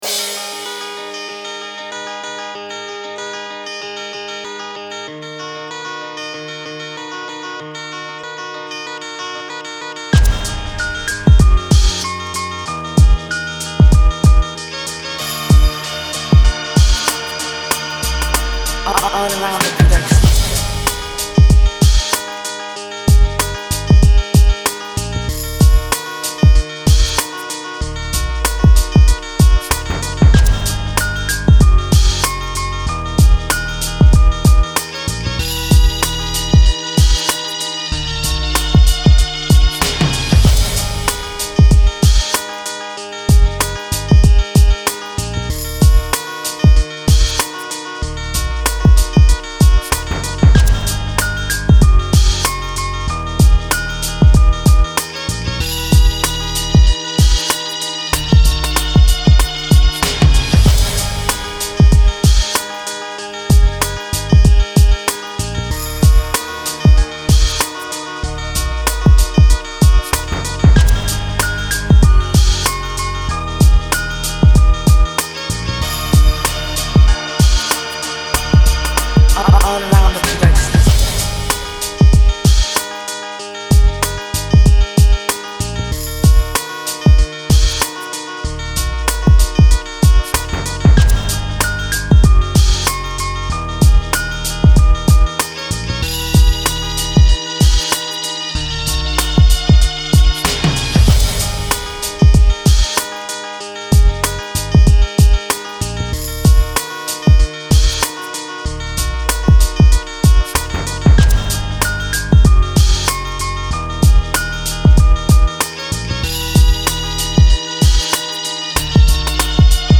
ловите лучшие 10 минусов, пряных, качающих, сочных.
минус 2